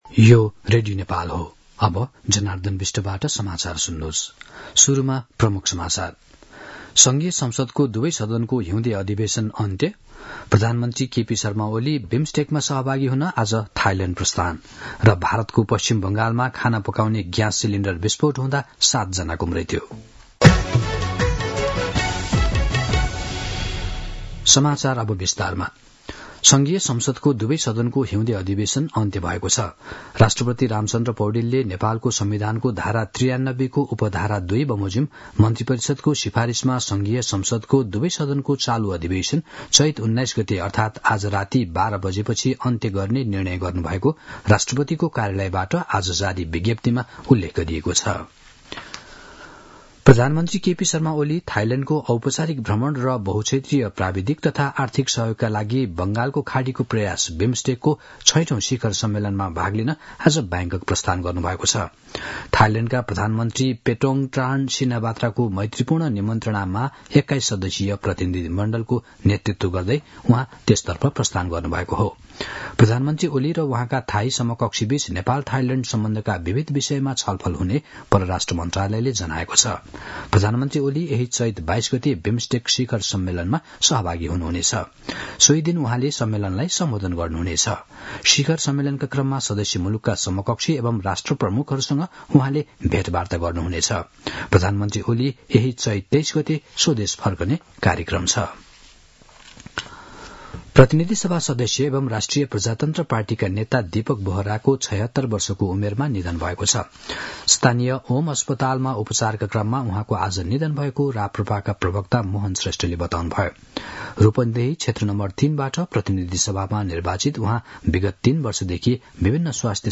दिउँसो ३ बजेको नेपाली समाचार : १९ चैत , २०८१
3-pm-news-.mp3